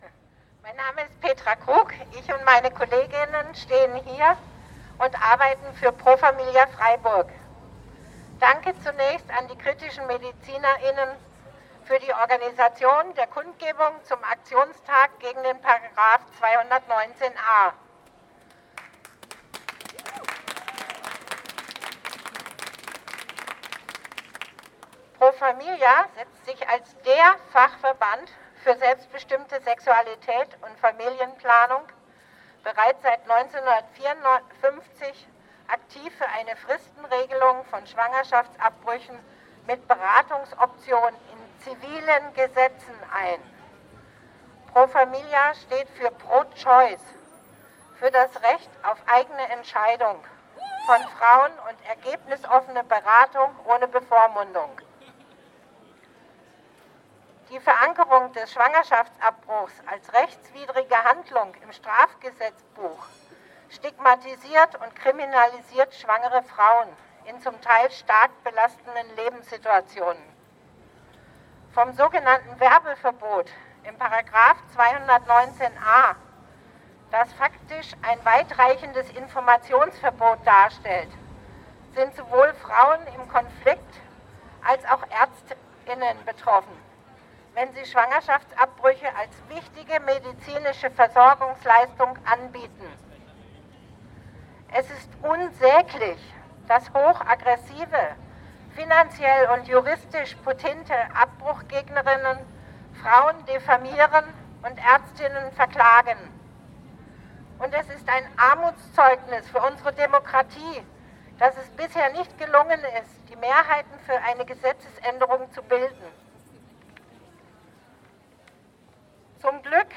Am 26.01.2019 fand im Rahmen eines bundesweiten Aktionstags für sexuelle Selbstbestimmung auch in Freiburg eine Kundgebung mit mehr als 100 Teilnehmer*innen statt.